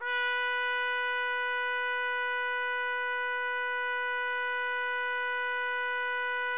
TRUMPET   18.wav